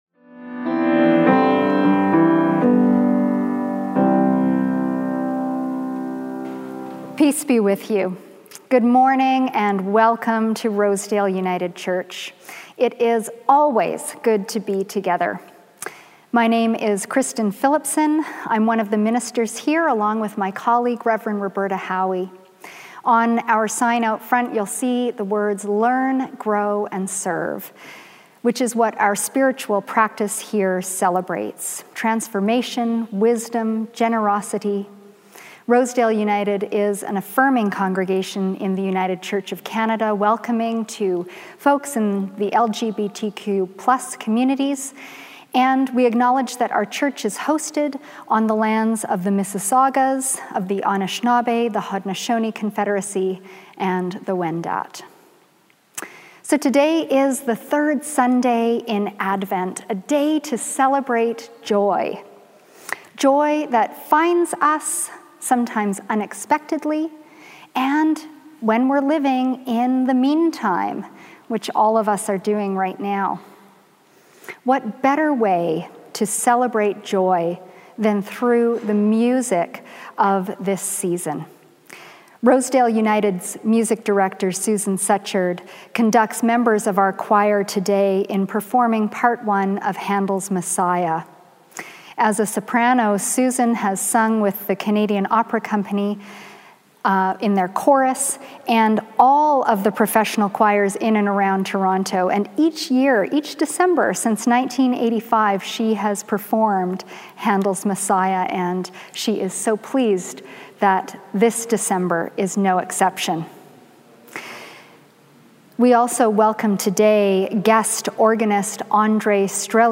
Rosedale United Services | Rosedale United Church